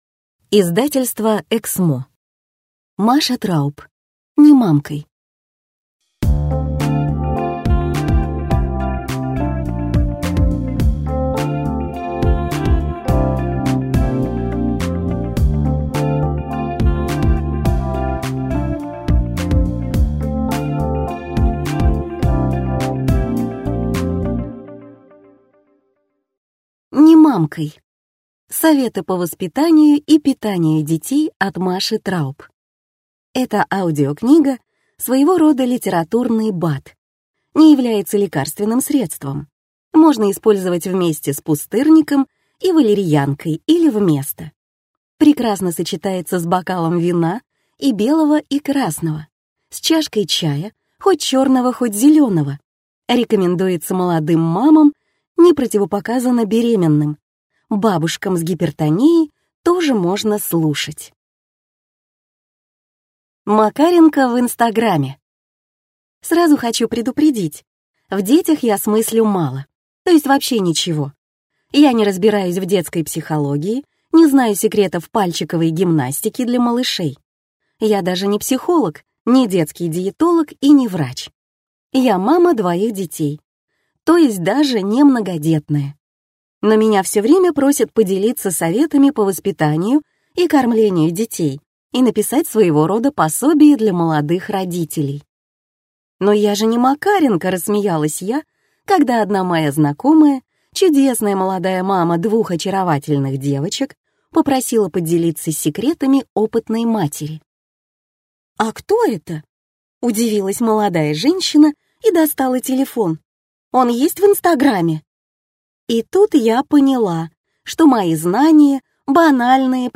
Аудиокнига Не мамкай!